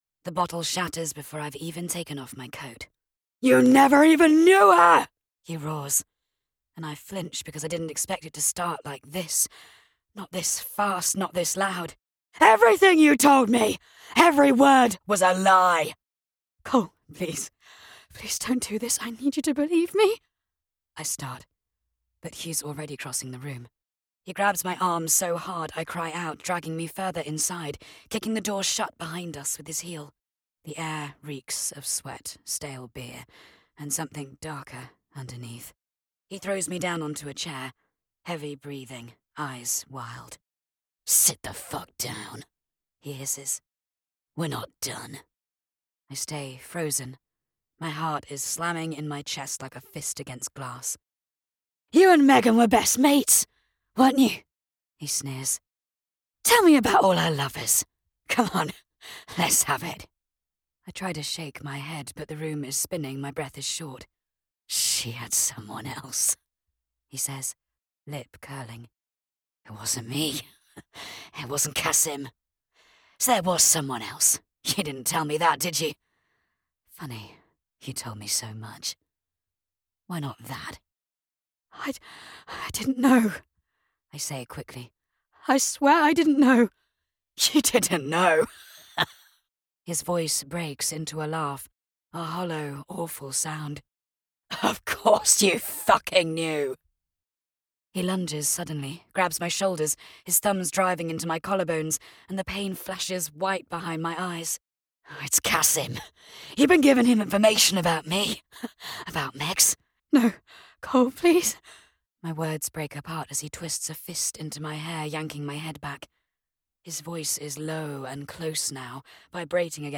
British Female Audiobook Narrator
Modern Thriller Download
1st person, F/M, English. Violent, dramatic.